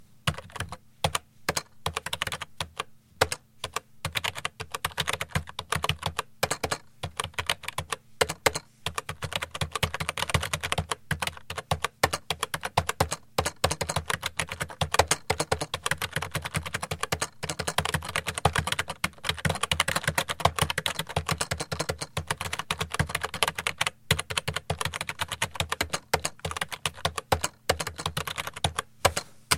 OFFICE AND PAPERWORK sounds " 01430 Keyboard writing 2
描述：只是键盘打字的rec by Shure BG 5.1
Tag: 计算机 按键 键盘 办公 类型 typying